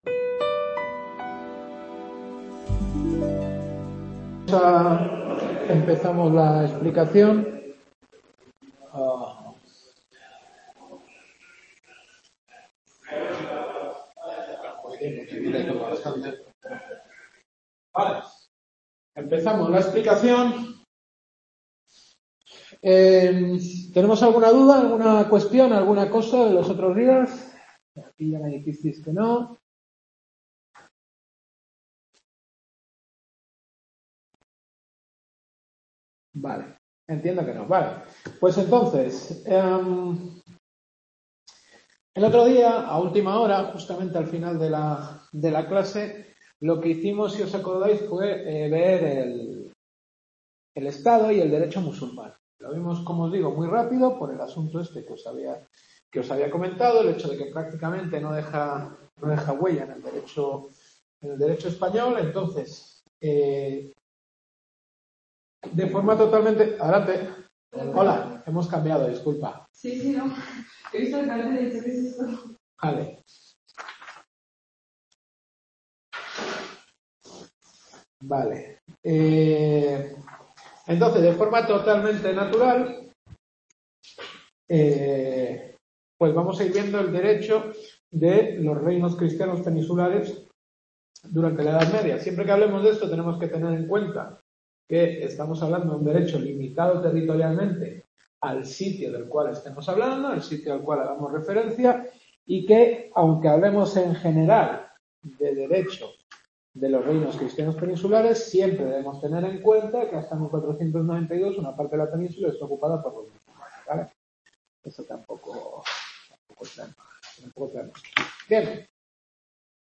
Historia del Derecho. Séptima Clase.